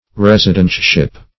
Meaning of residentship. residentship synonyms, pronunciation, spelling and more from Free Dictionary.
Search Result for " residentship" : The Collaborative International Dictionary of English v.0.48: Residentship \Res"i*dent*ship\ (r?z"?-dent-sh?p), n. The office or condition of a resident.